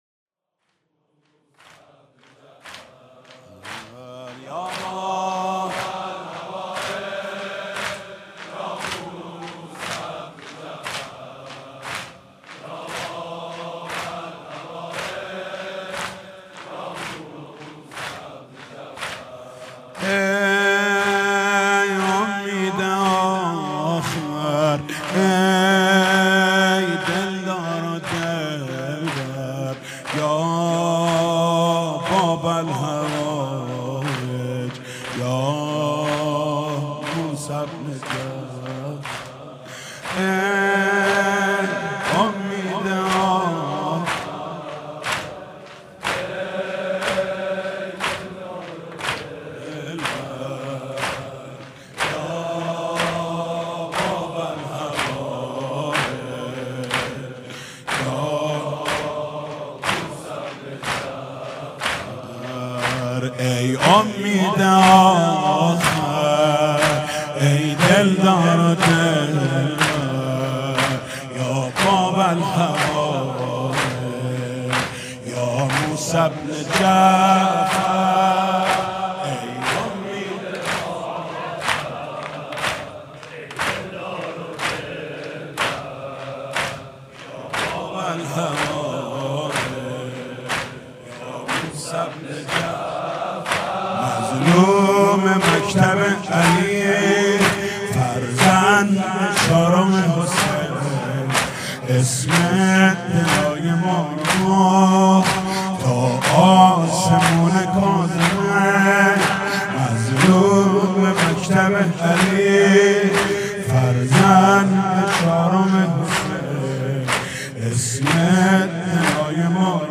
مداحی نوحه